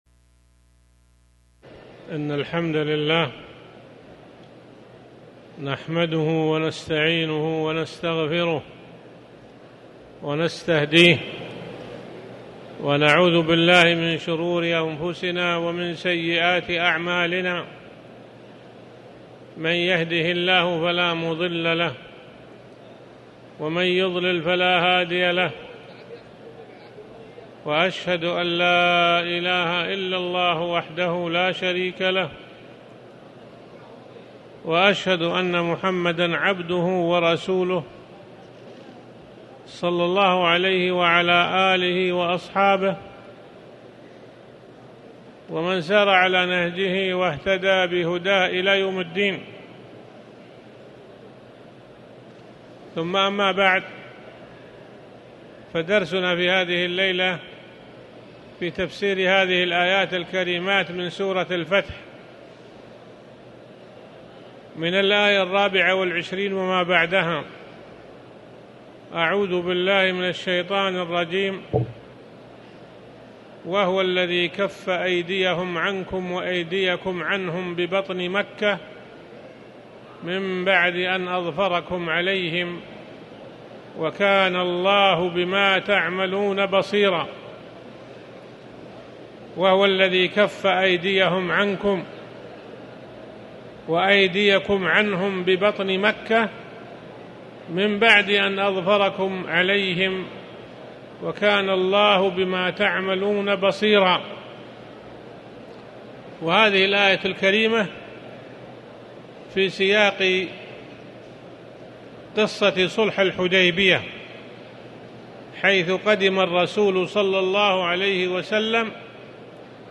تاريخ النشر ٣٠ ذو الحجة ١٤٣٩ هـ المكان: المسجد الحرام الشيخ